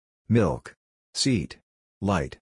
• milk – /mɪlk/ – /i/
• seat – /siːt/ – /i:/
• light – /laɪt/ – /ai/